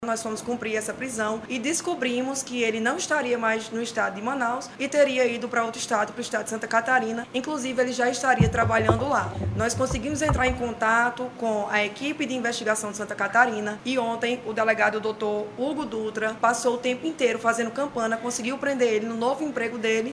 Em coletiva de imprensa